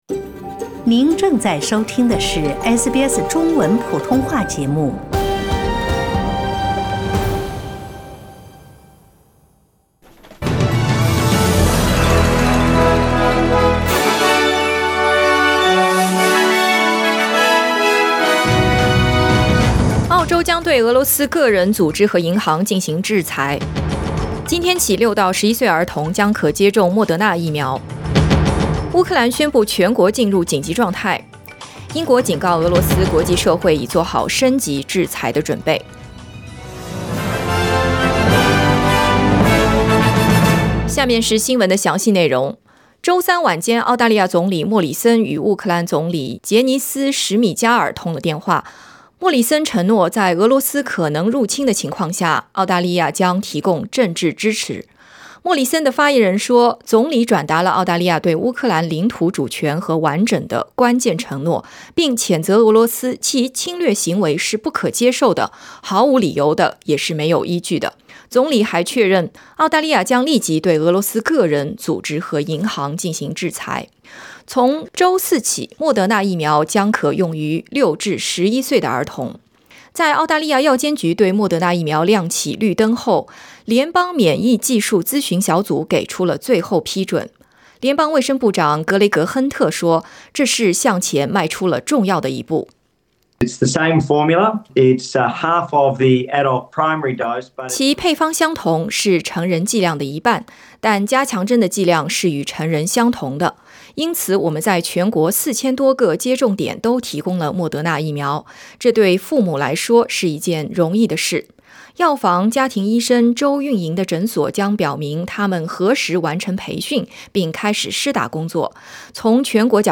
SBS早新闻 (2022年2月24日)
SBS Mandarin morning news Source: Getty Images